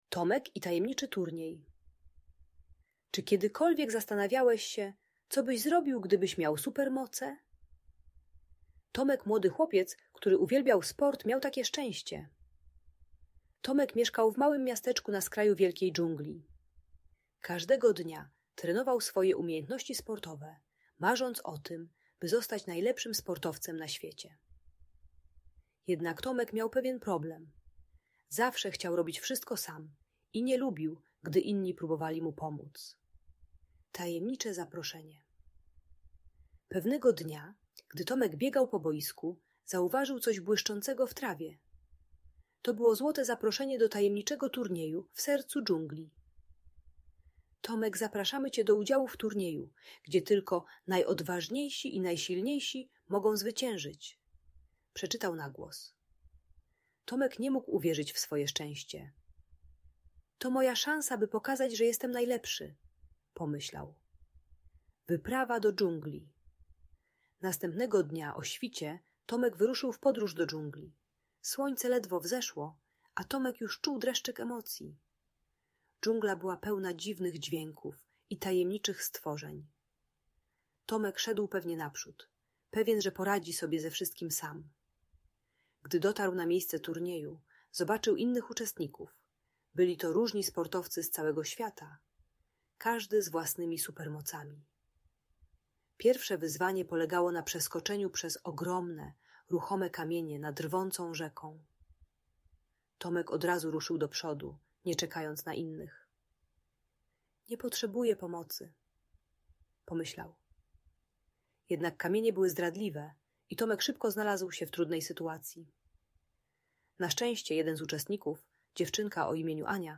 Tomek i Tajemniczy Turniej: Opowieść o Współpracy - Audiobajka